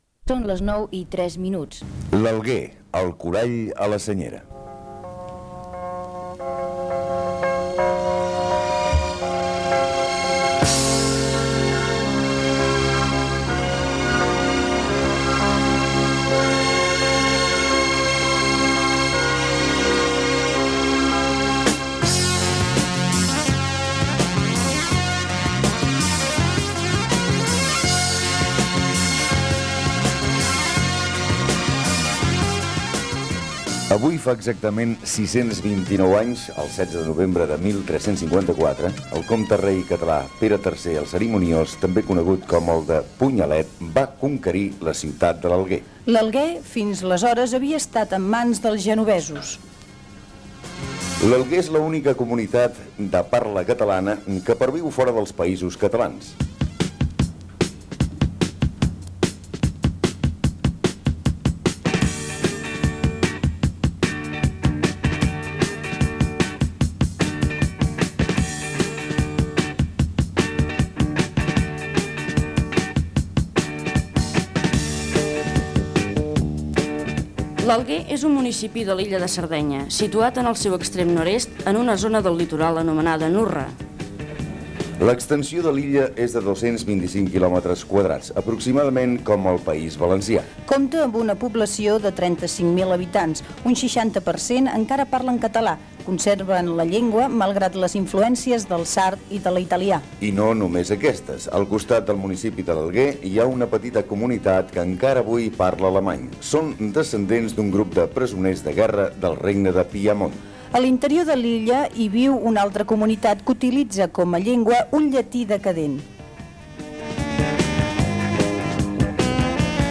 Hora, presentació del programa dedeicada a la història de la ciutat de l'Alguer a Sardenya, amb la participació del professor Jordi Carbonell Gènere radiofònic Divulgació